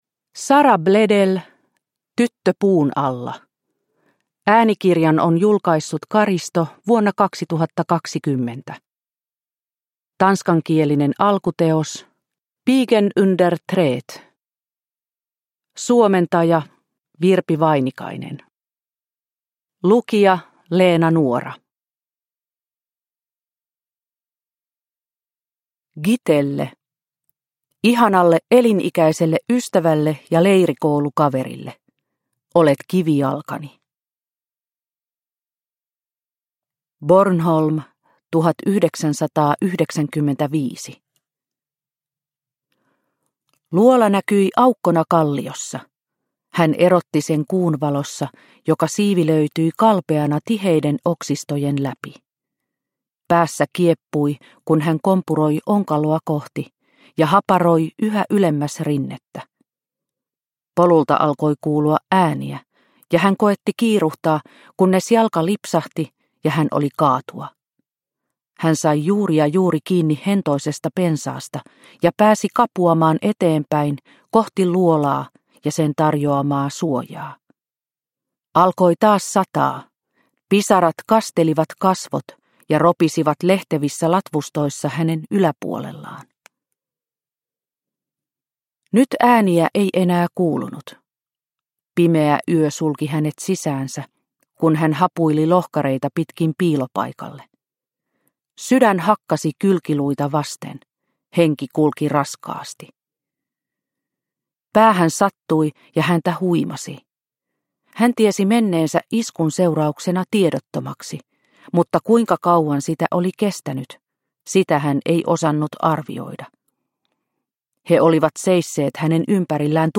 Tyttö puun alla – Ljudbok – Laddas ner